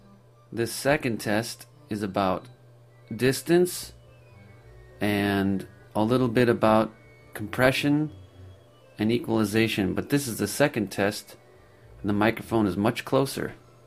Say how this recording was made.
waveforms, or large waveforms, but the closer mic is simply a clearer vocal recording. But keep in mind that closer isn't always voc 2 (closer) AUD_voice_close_comp.mp3